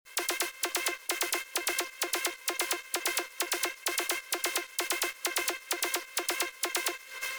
Runner_130 – Fx_Full
bass house construction kit drops
Runner_-1-Runner_130-Fx_Full.mp3